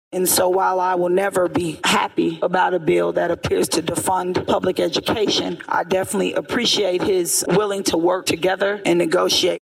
Senator Barbara Anne Washington, D-Kansas City, spoke on talks taken with the bill:
Audio provided by the Missouri Senate